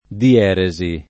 dieresi [ di- $ re @ i ] s. f.